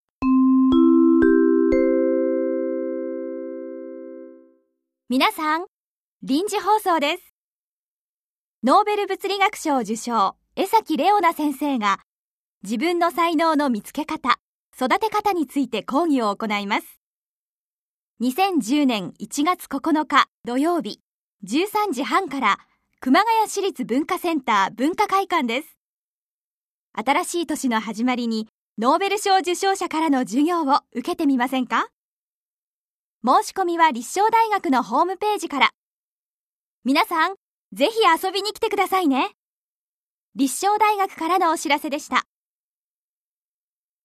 音声サンプル